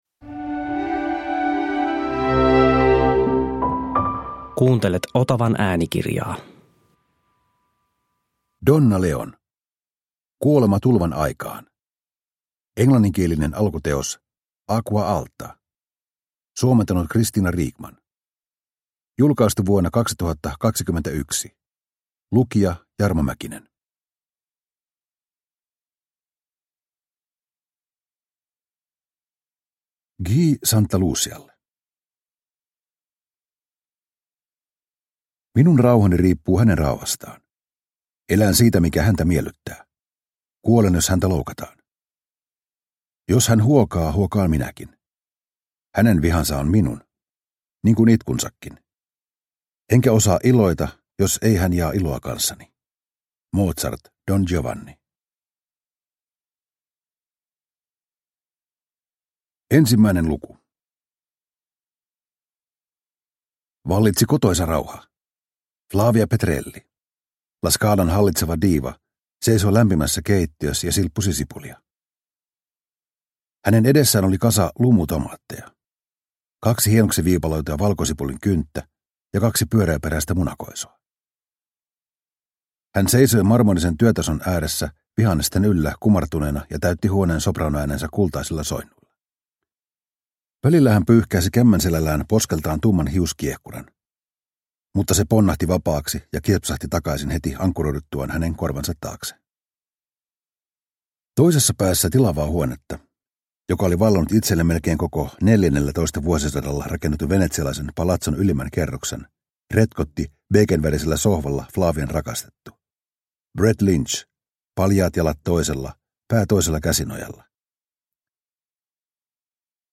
Kuolema tulvan aikaan – Ljudbok – Laddas ner